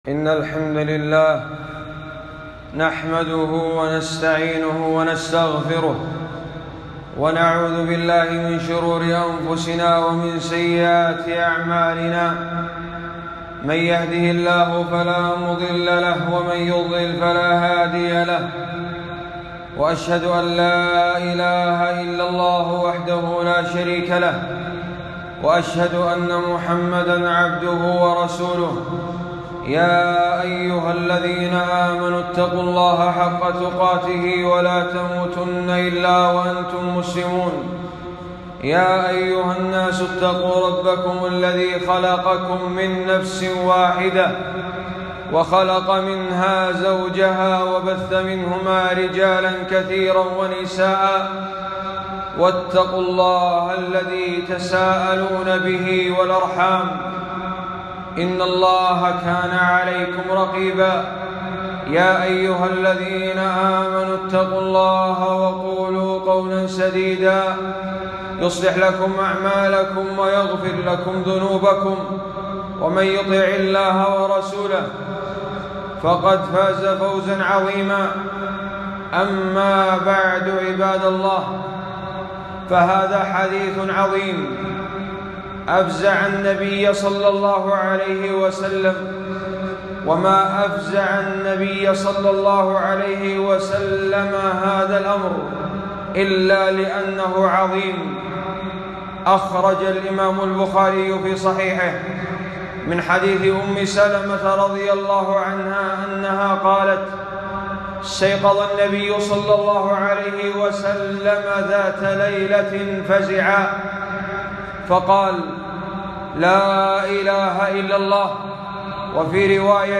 خطبة - رب كاسية في الدنيا عارية في الآخرة